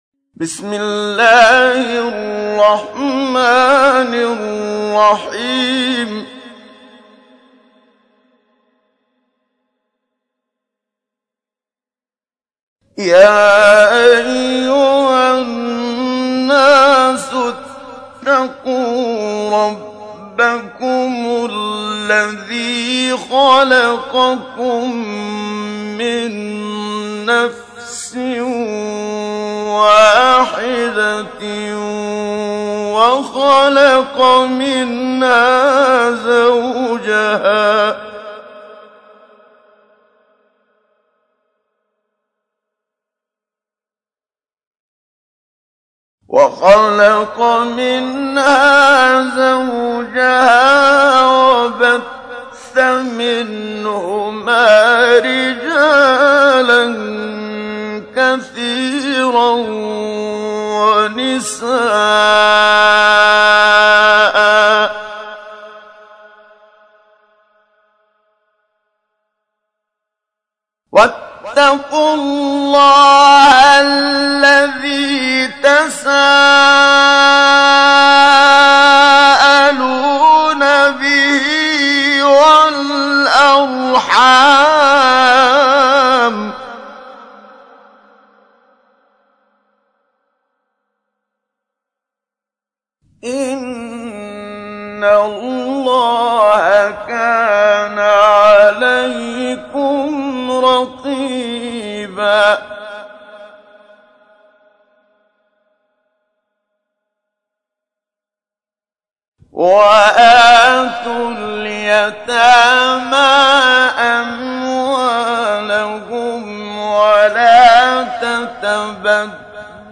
تحميل : 4. سورة النساء / القارئ محمد صديق المنشاوي / القرآن الكريم / موقع يا حسين